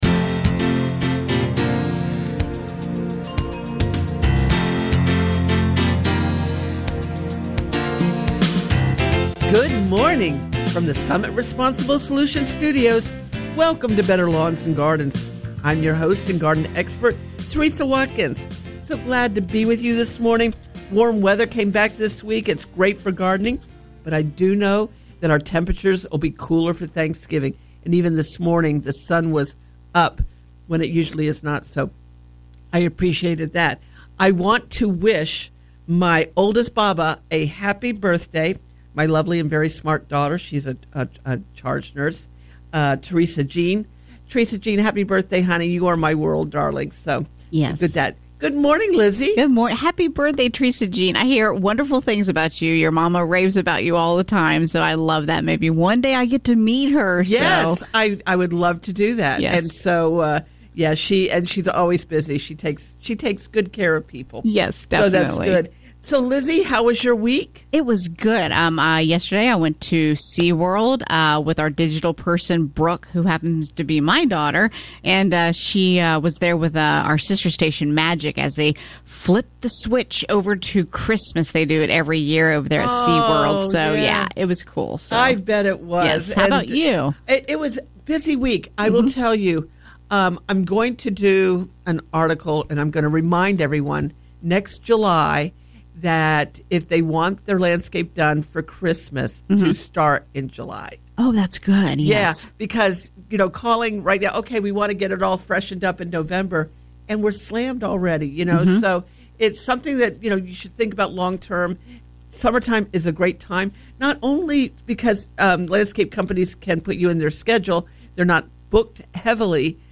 Better Lawns and Gardens Hour 1 – Coming to you from the Summit Responsible Solutions Studios. Oranges, tangerines, and grapefruit, oh my!